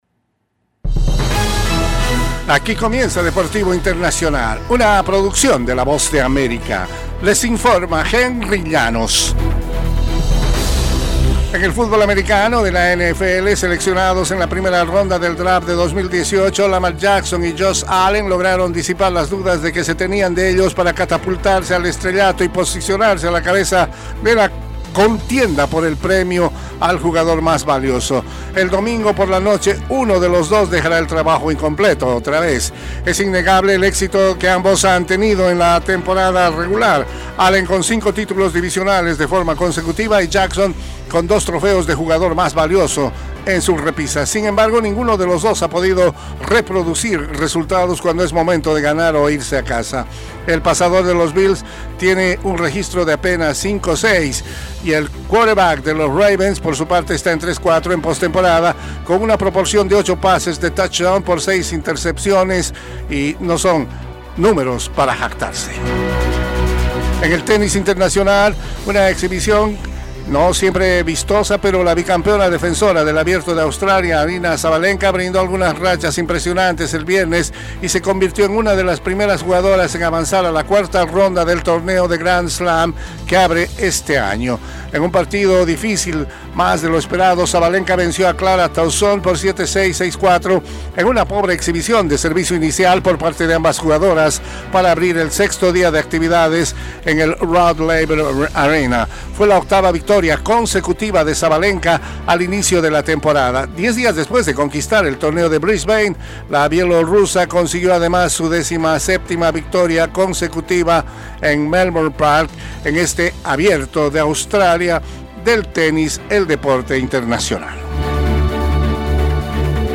1 6:00 PM | AVANCE [Audio] - enero 17, 2025 2:59 Play Pause 25m ago 2:59 Play Pause Reproducir más Tarde Reproducir más Tarde Listas Me gusta Me gusta 2:59 El siguiente es un avance informativo de la Voz de América.
["Avance Informativo" es un segmento de noticias de la Voz de América para nuestras afiliadas en la región de América Latina y el Caribe].Por VOA … continue reading